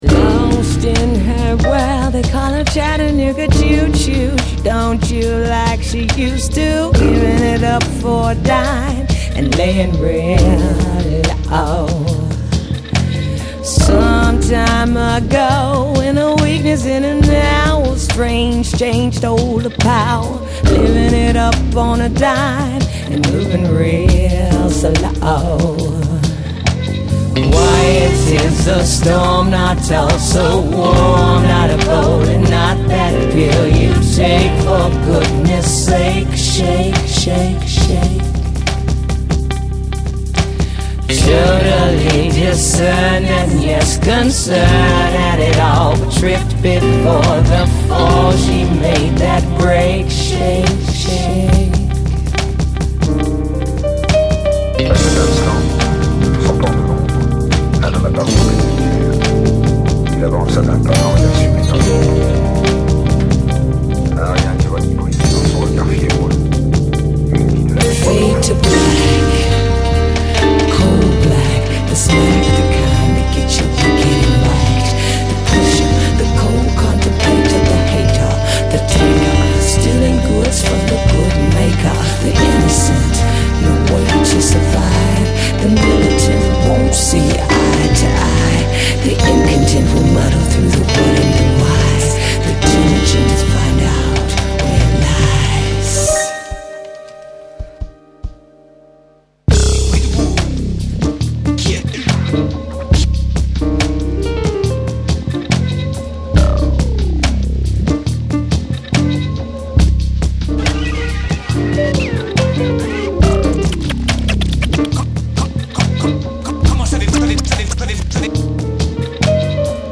Oboe
Solo Oboist
Urban, Soul, Drum & Bass:
The mechancholy of these hypnotic sounds is virtual.